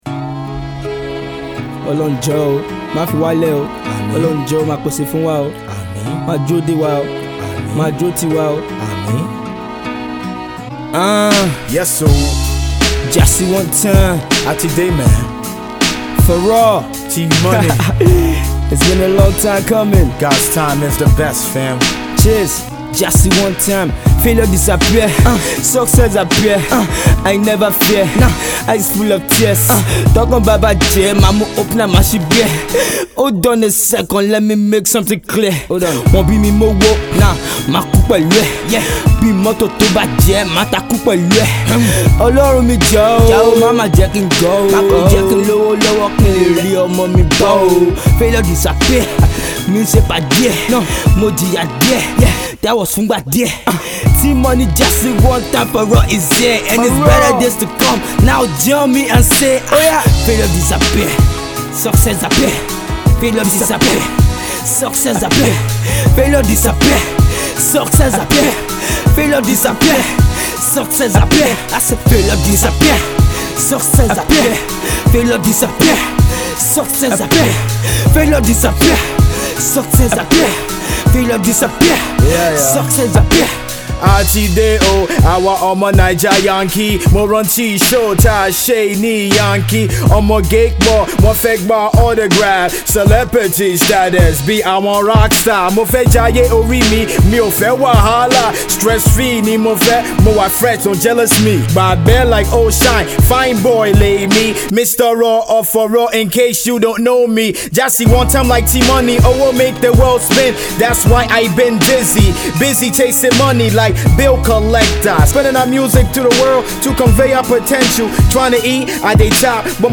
Hip-Hop
clashing on this sombre production with words of hope.